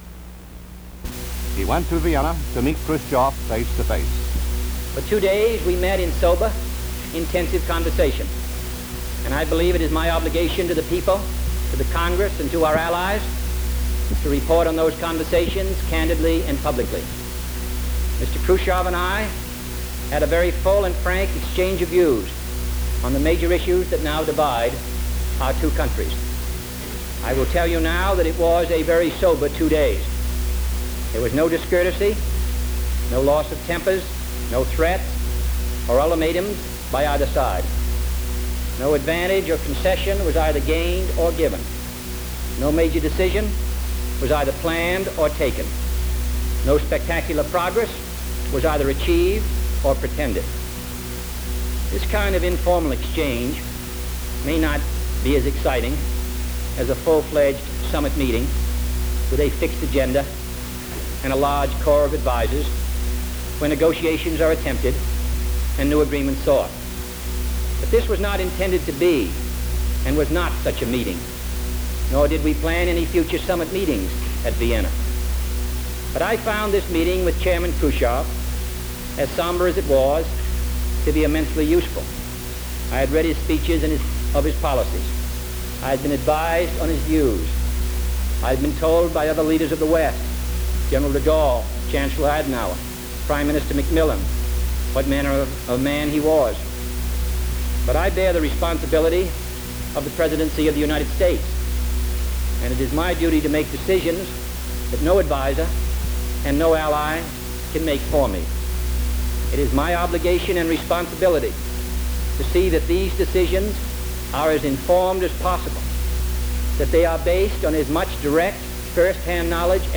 Khrushchev at summit meeting press conference